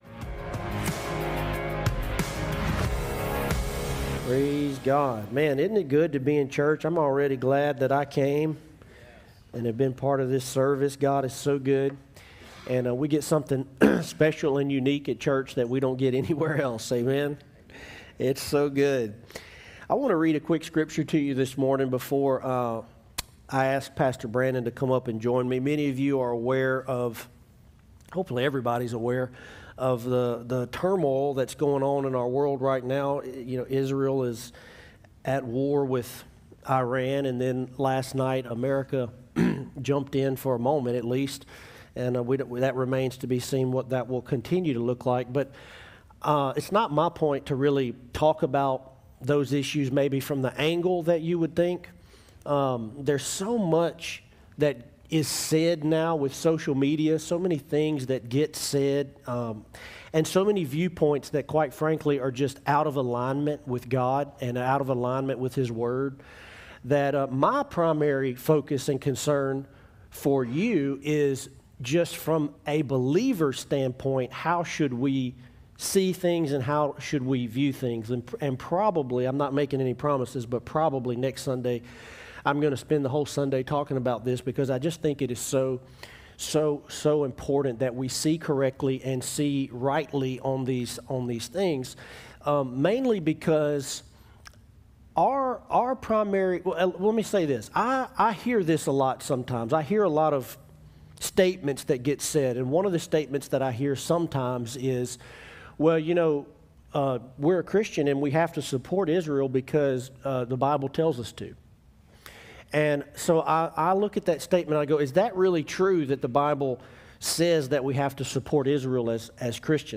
Today, we have a powerful and deeply honest conversation